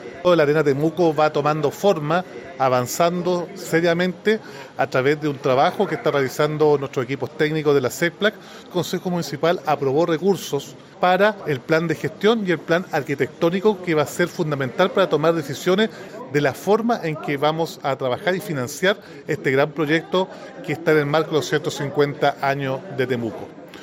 Roberto-Neira-alcalde-Temuco-Arena-Temuco.mp3